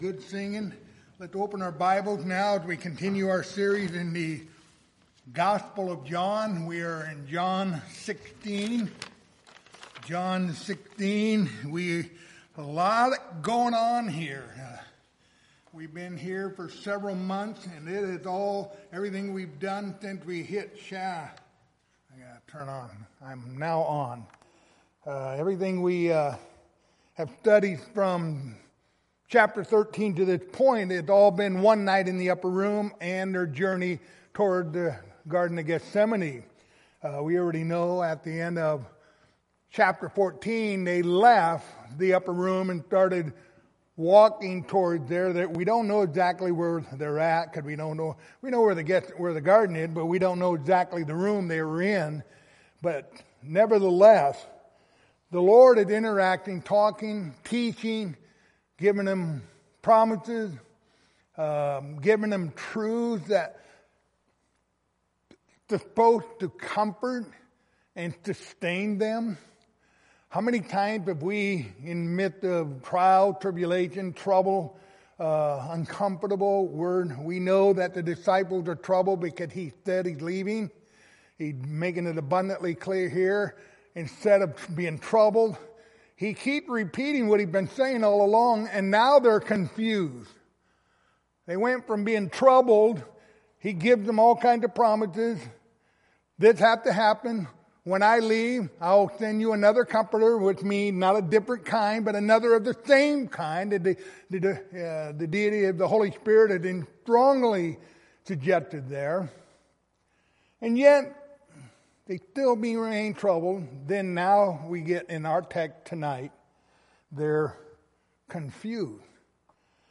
Passage: John 16:16-22 Service Type: Wednesday Evening